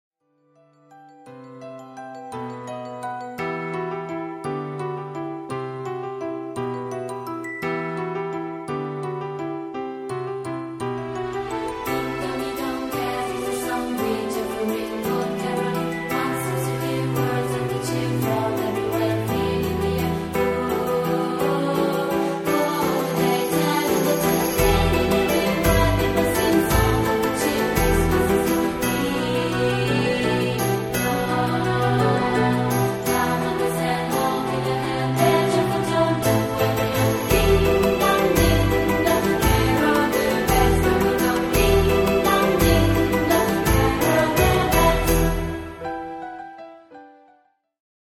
Contralti